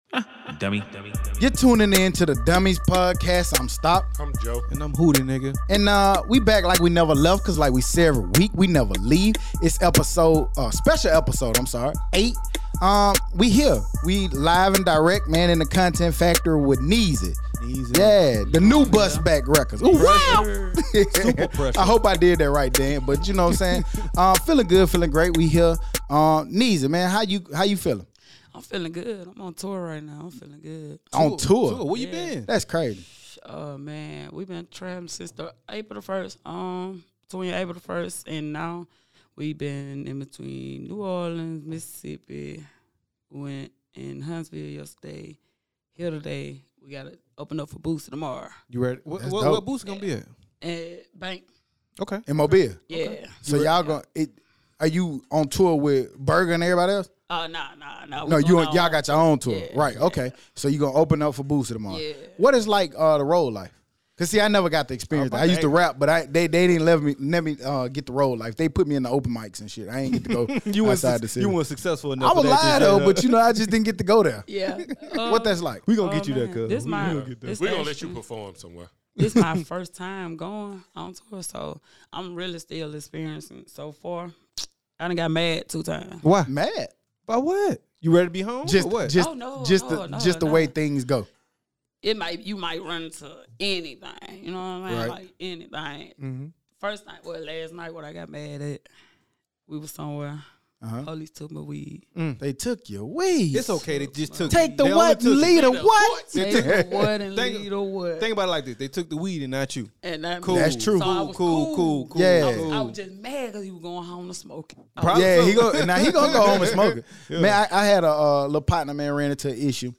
Listen in as we dive in to hear about what’s it like to get to perform in all of these different cities while out on the road, and what does it feel like to get to open for artist who they both idolize. As always, you can expect that same high energy and laughter right here on this brand new special episode of The Dummies Podcast!